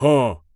Male_Grunt_Hit_Neutral_07.wav